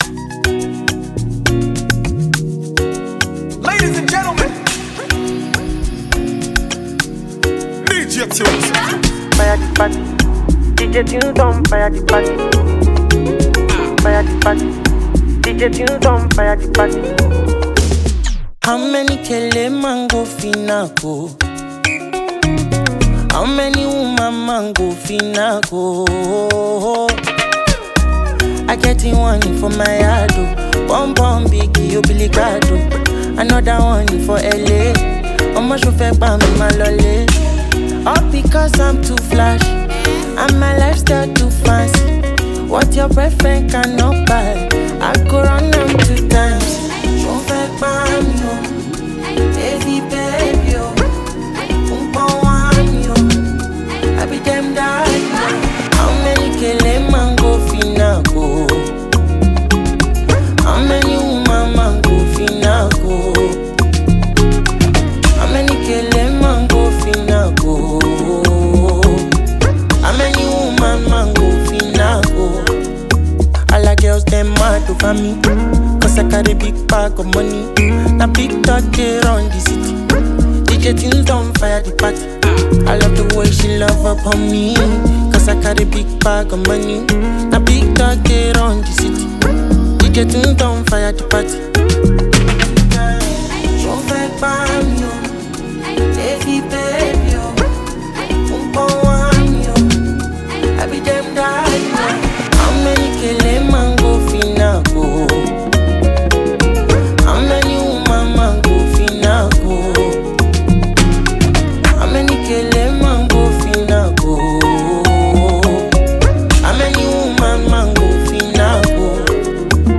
Nigerian singer